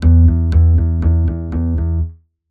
PIZZICATOｼ医ヴ繝√き繝ｼ繝茨ｼ
PIZZICATO縺ｯ謖縺ｧ縺ｯ縺倥＞縺ｦ髻ｳ繧貞ｺ縺吝･乗ｳ輔〒縺吶よ沐繧峨°縺上い繧ｳ繝ｼ繧ｹ繝繧｣繝繧ｯ縺ｪ髻ｿ縺阪′迚ｹ蠕ｴ縺ｧ縲∬誠縺｡逹縺縺溘ル繝･繧｢繝ｳ繧ｹ繧郢顔ｴｰ縺ｪ陦ｨ迴ｾ繧貞刈縺医◆縺縺ｨ縺阪↓菴ｿ縺縺ｾ縺吶